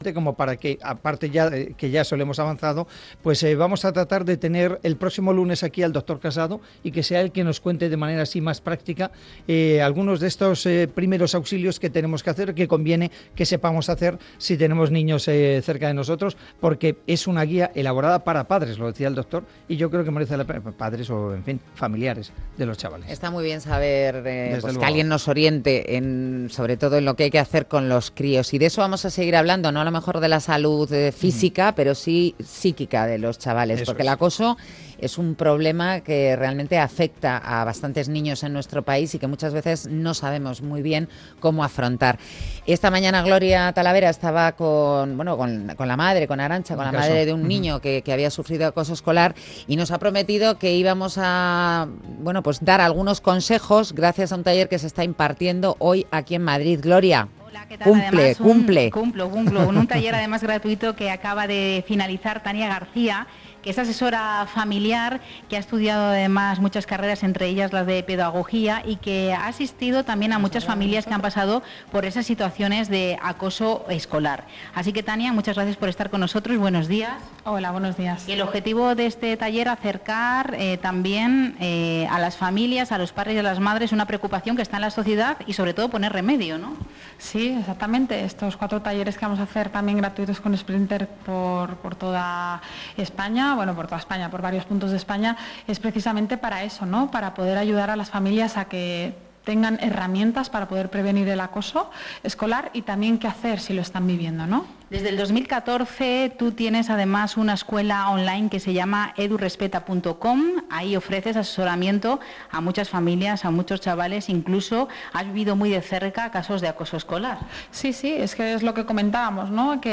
Entrevista en Onda Madrid